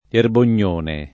[ erbon’n’ 1 ne ]